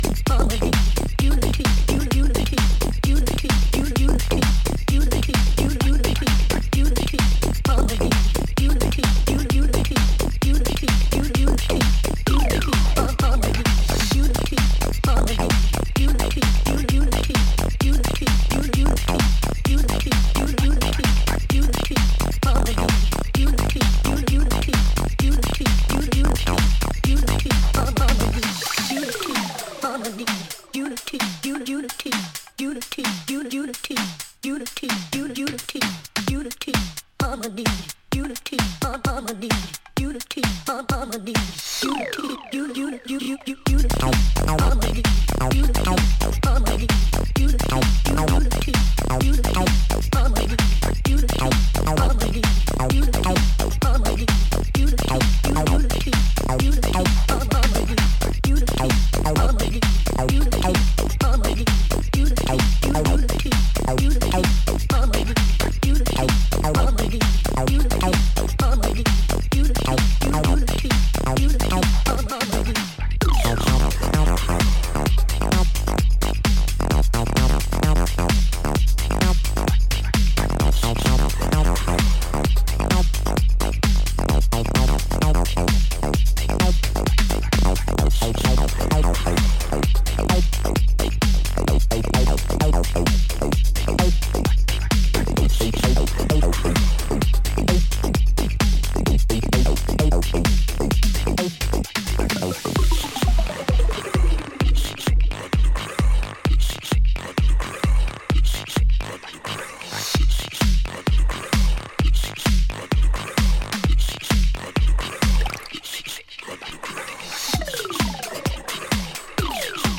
軟性でバウンシーなエレクトロ・ファンク路線のドラムとアシッドリフ、ヴォイスサンプルが絡む